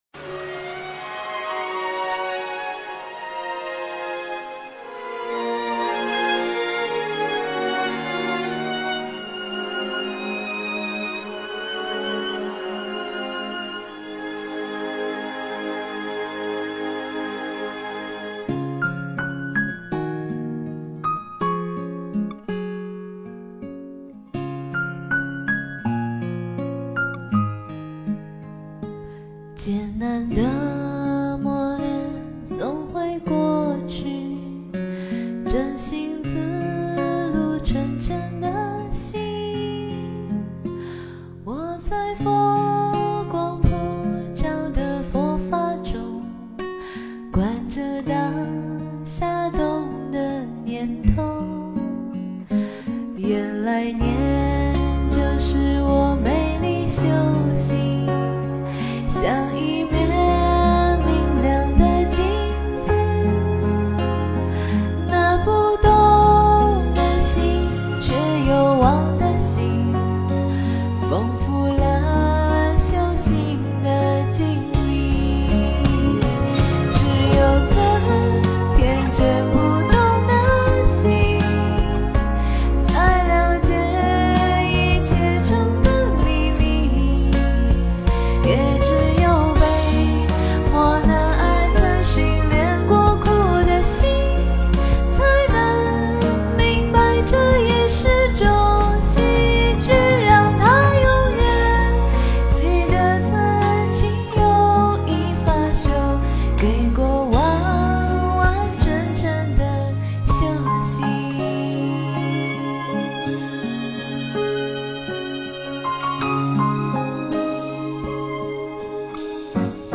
标签: 佛音 诵经 佛教音乐